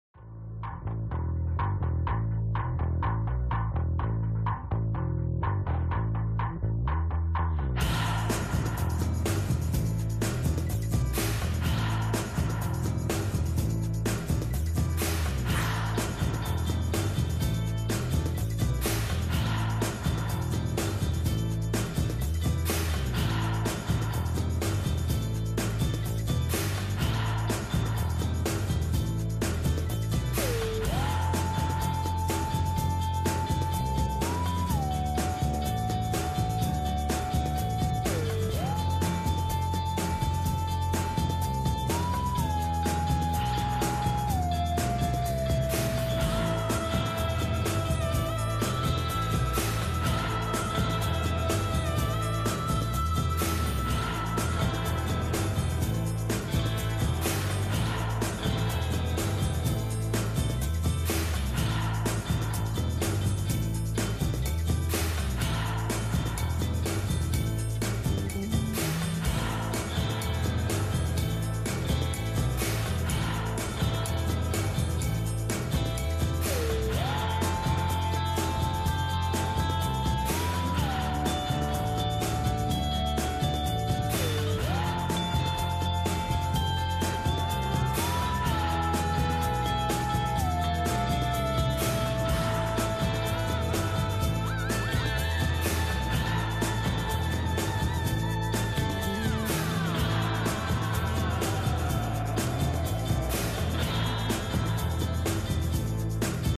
Помогите, пожалуйста, опознать инструментал